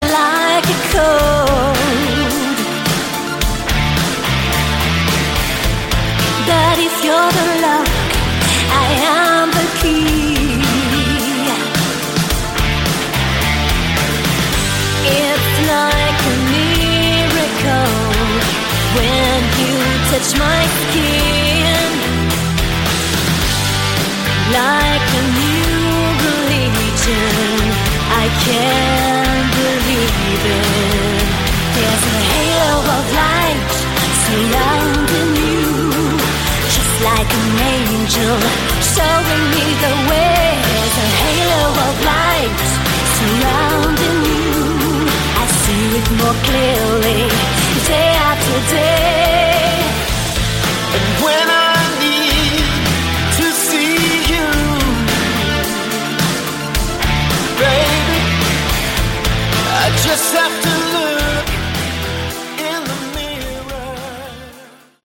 Category: Melodic Rock
lead and backing vocals